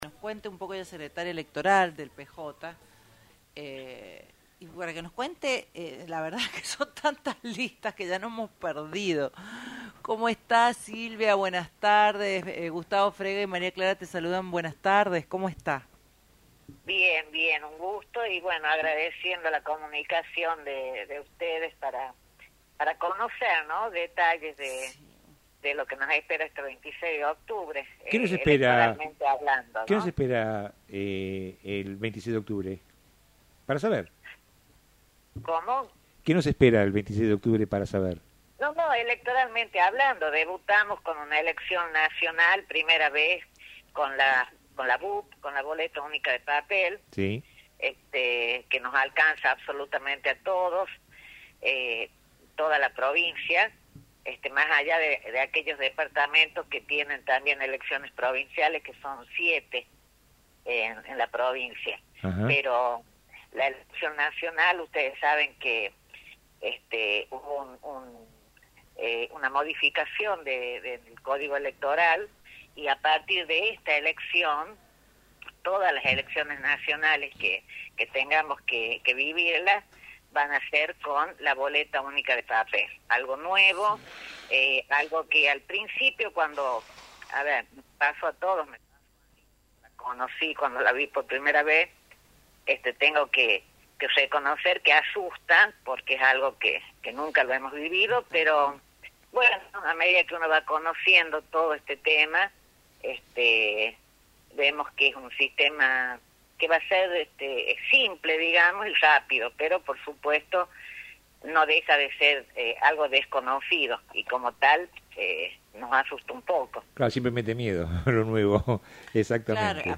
En diálogo telefónico con Radio Libertad